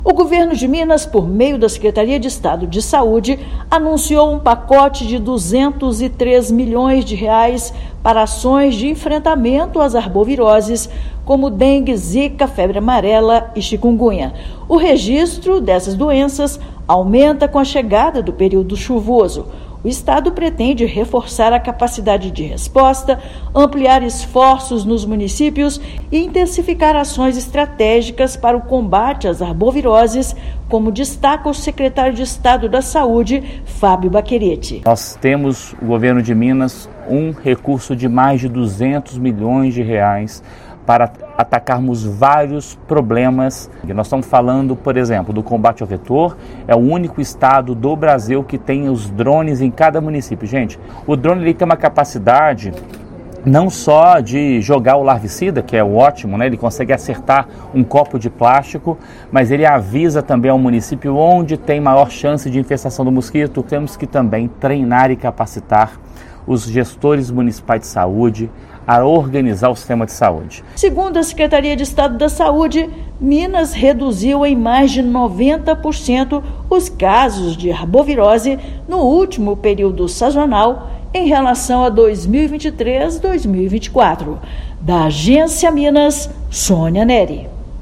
Com planejamento, inovação e descentralização, Estado reforça estratégias para manter queda expressiva dos casos e ampliar a proteção da população mineira. Ouça matéria de rádio.